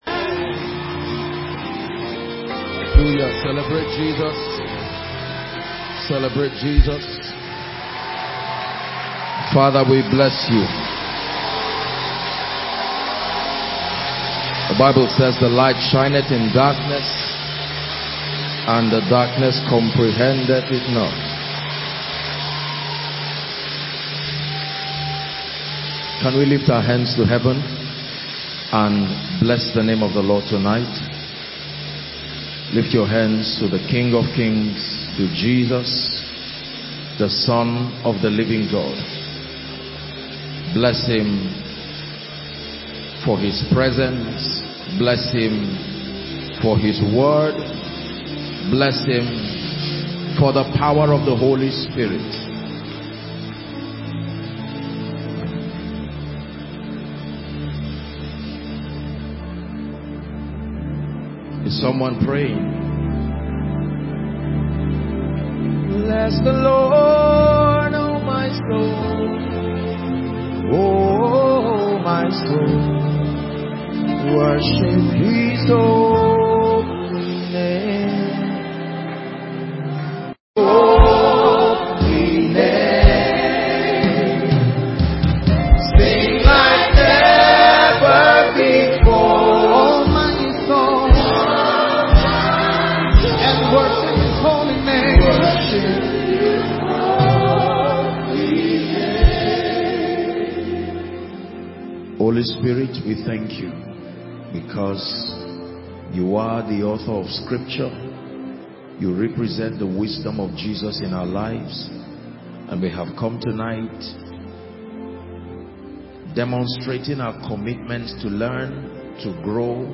In this powerful sermon